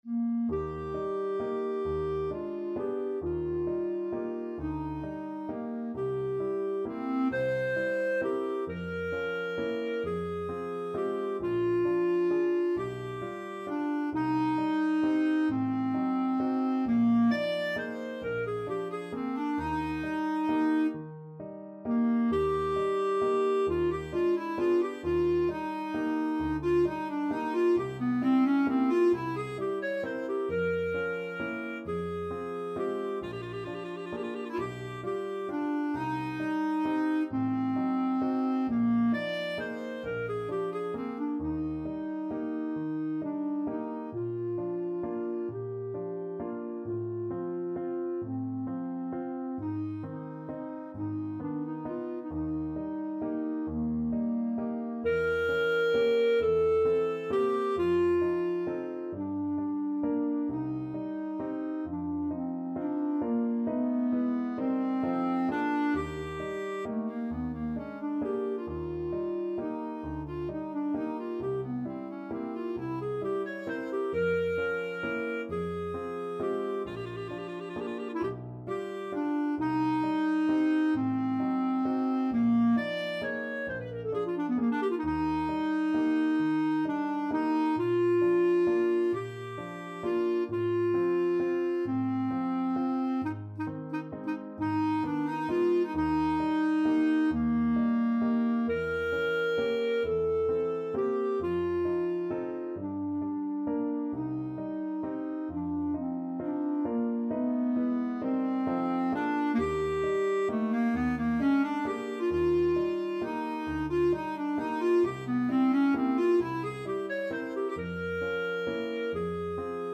Classical Chopin, Frédéric Nocturne Op.9 No.2 Clarinet version
ClarinetPianoClarinet (Eb oct high)
12/8 (View more 12/8 Music)
Andante . = 44
Eb major (Sounding Pitch) F major (Clarinet in Bb) (View more Eb major Music for Clarinet )
Classical (View more Classical Clarinet Music)
Nostalgic Music for Clarinet